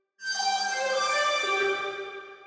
クイズの解答音
「クイズ系効果音」「解答音」